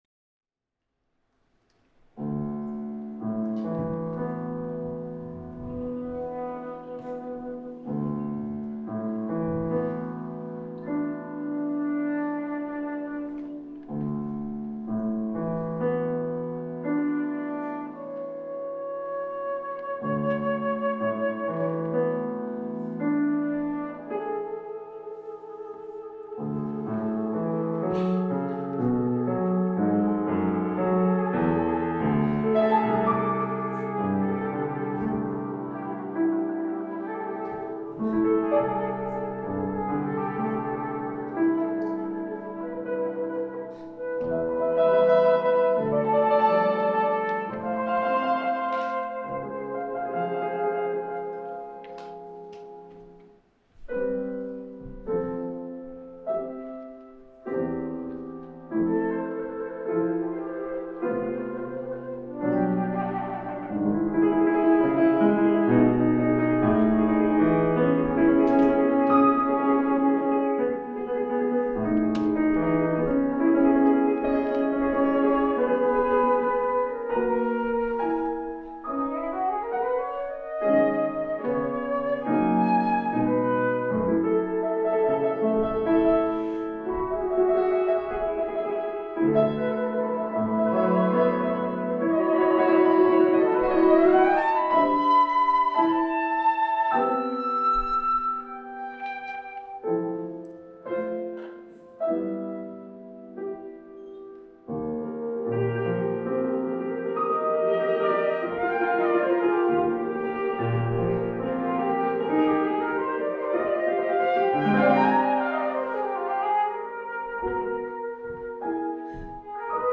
Scored for: flute and piano Duration: 6 min.